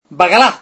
AUDIO: El famoso grito de Maldini.... ¡¡Bakalá!!